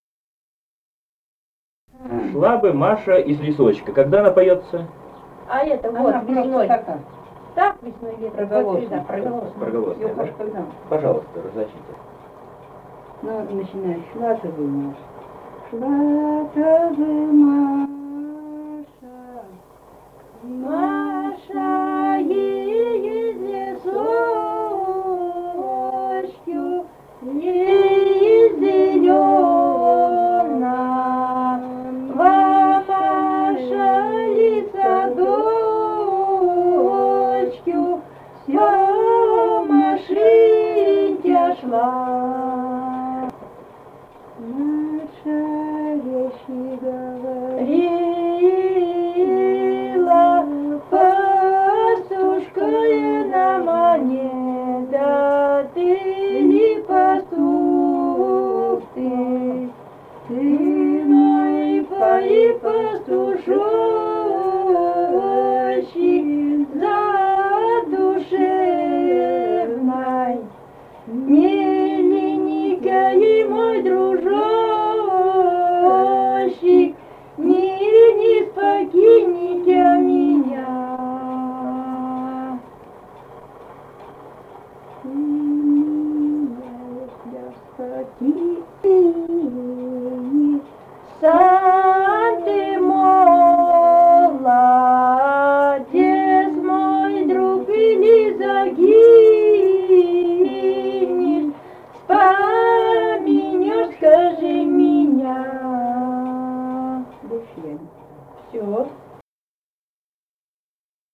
Файл:63a Шла-то бы Маша из лесочку И1774-14а (дуэт) Белое.wav.mp3 — Фолк депозитарий
Республика Казахстан, Восточно-Казахстанская обл., Катон-Карагайский р-н, с. Белое, июль 1978.